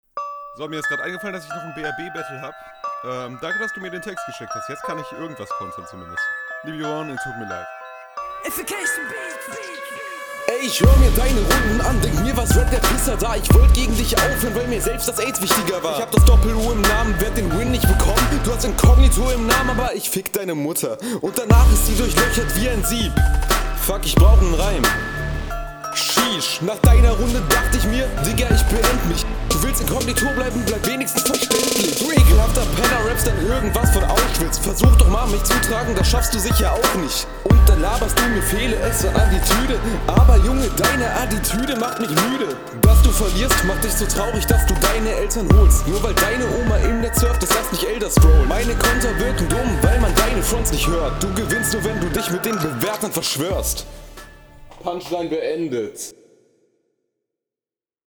Flow: Teilweise etwas off, aber man kann dazu besser viben als bei der HR.
Flow: Hier auf jeden Fall besser als sein Gegner, gab aber auch ein paar Fehler.